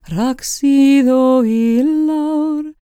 L CELTIC A02.wav